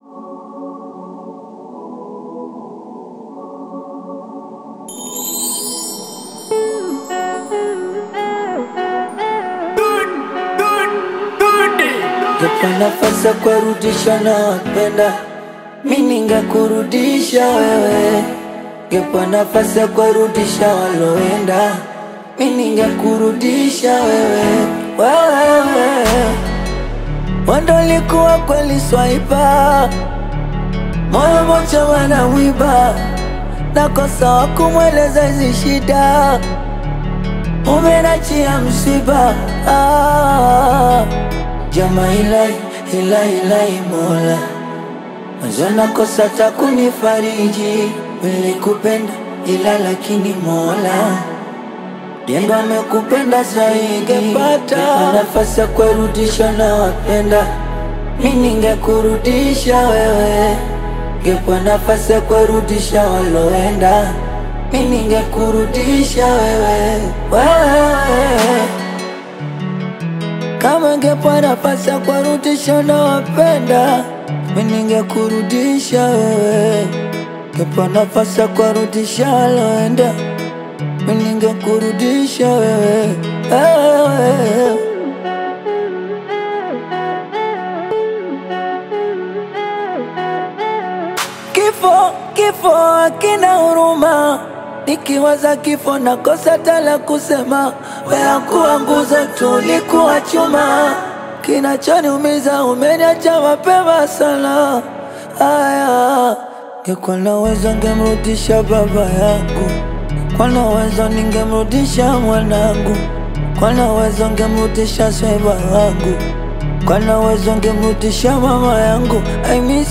soulful Bongo Flava single